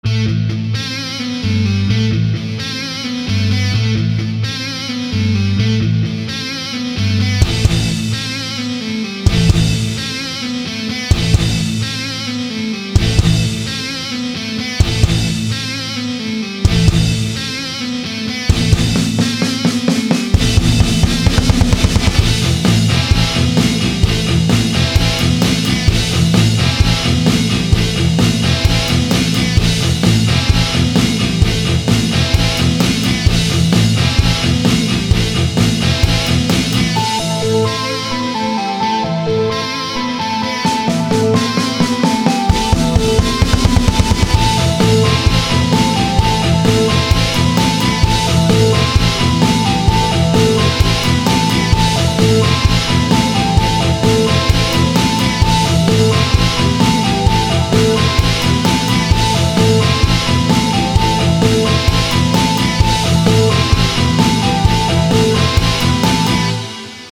Yok böyle bir ses kalitesi. Buyrun yalnızca bir kanalda Guitar Rig kullanılarak yapılmış son denemem "Dead" :)
Edit : Hacı sonradan synth falan ekledim içine fazla kuru kalmıştı :)